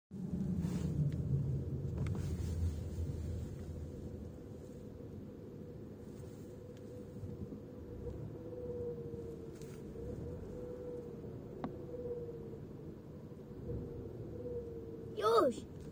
Domyślnie 1.5 ecoboost benzyna dziwne dźwięki
Na niskich obrotach (tak do 1400 choć coraz częściej nawet i do 1800 bo problem narasta) słyszę dziwny gwizd / szum (trochę jakby silny wiatr za oknem) .
W 8 sekundzie pojawiają się pierwsze istotne oglosy.
Zaznaczam, że nie chodzi o stukot czy inne opisywane na forum objawy dzwonienia.
mondeo_irytujacy_dzwiek.aac